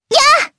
Lewsia_A-Vox_Attack4_jp.wav